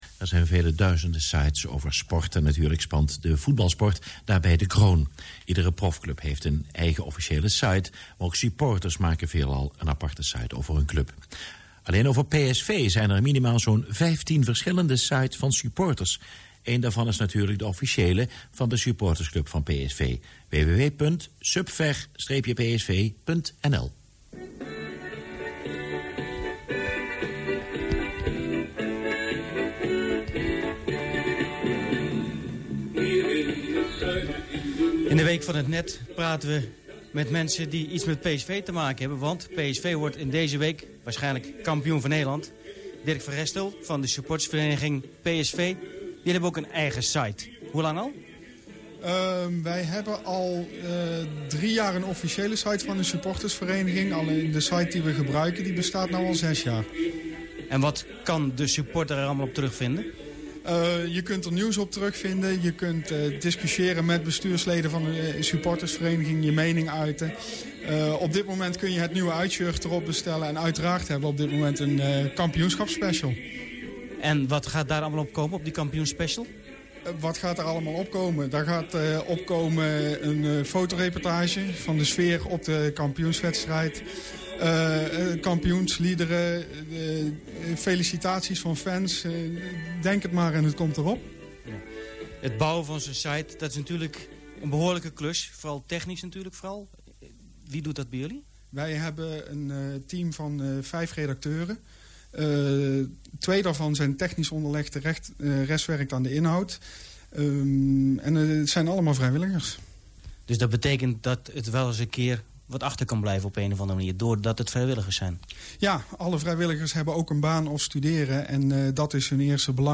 interview-supver-psv.mp3